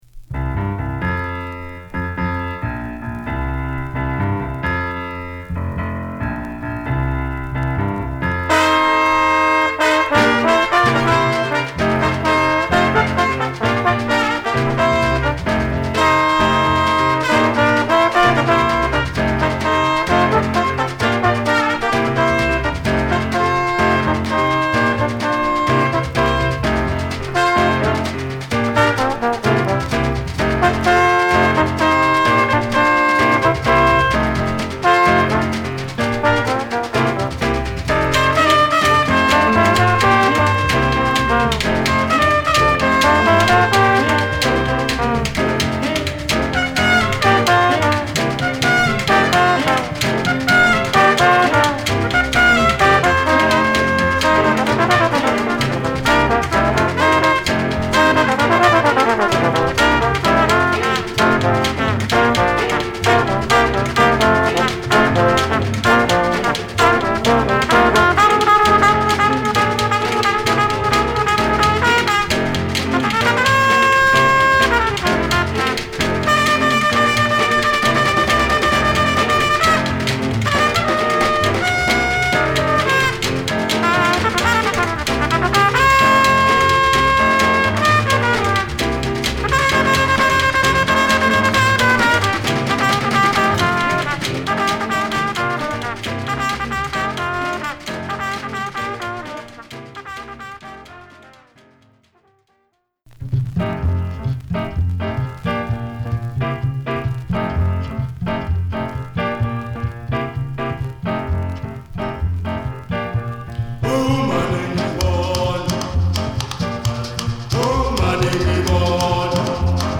」はじめ、唯一無二のホッコリしたアフロ・ジャズを披露！